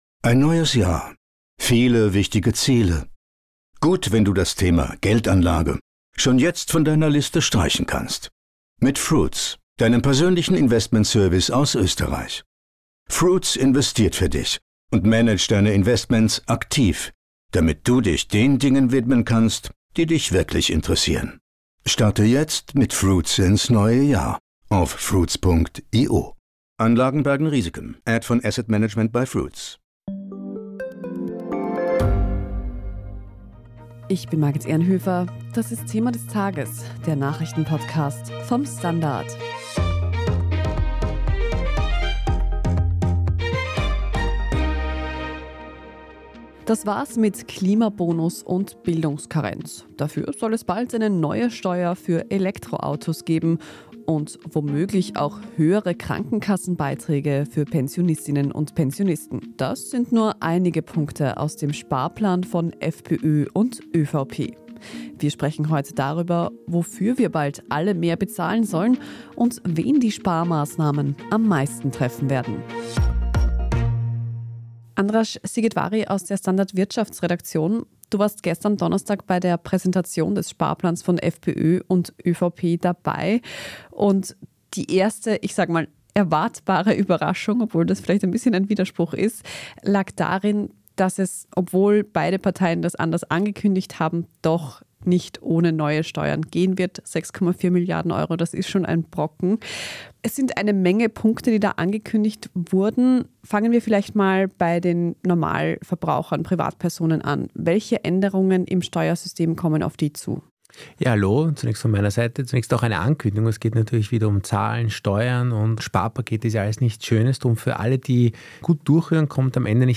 "Thema des Tages" ist der Nachrichten-Podcast vom STANDARD.